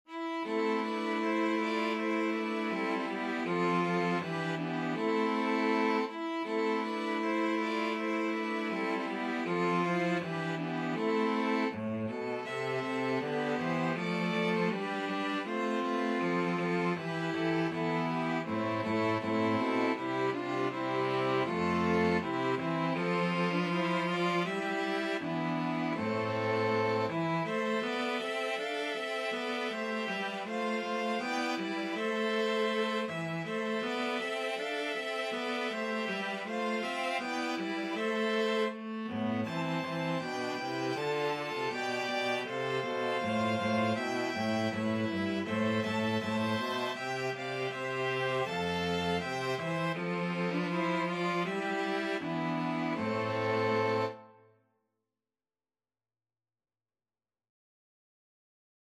Free Sheet music for String Quartet
Violin 1Violin 2ViolaCello
C major (Sounding Pitch) (View more C major Music for String Quartet )
2/4 (View more 2/4 Music)
Traditional (View more Traditional String Quartet Music)
world (View more world String Quartet Music)